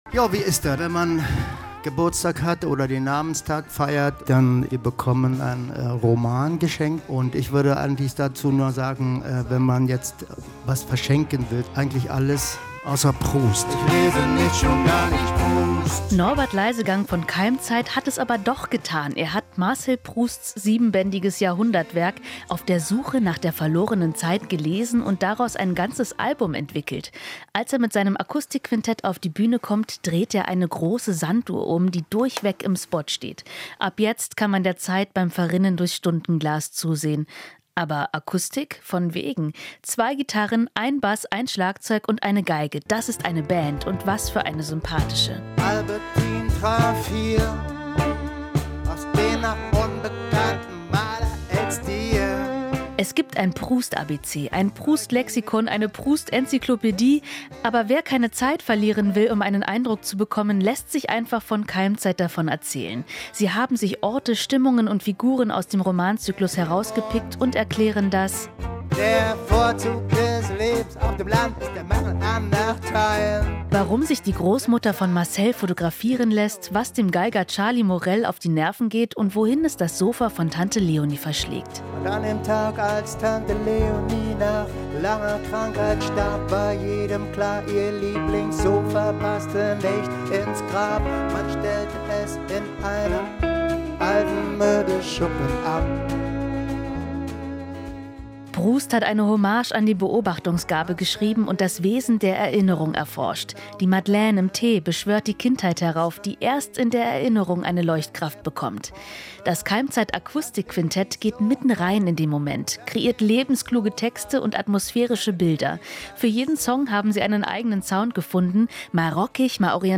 Inforadio Nachrichten, 12.05.2023, 00:00 Uhr - 13.05.2023